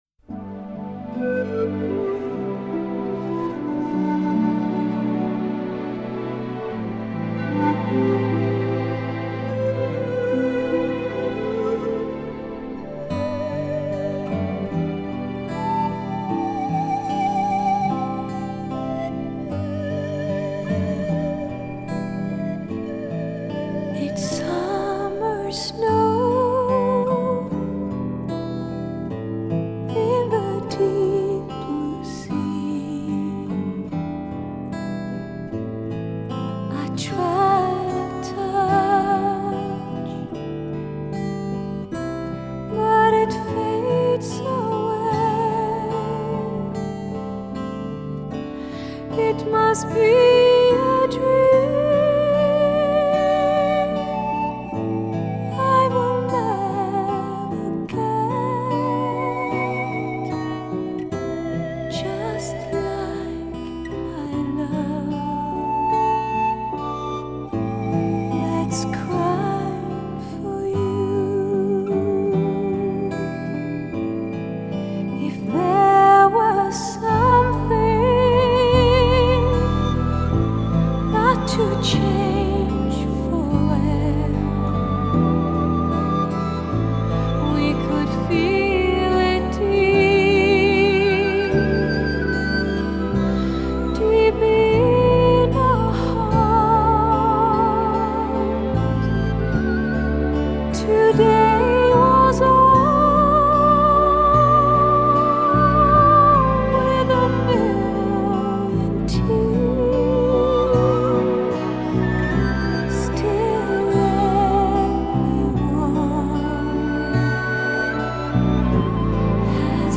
古典流行跨界美声天后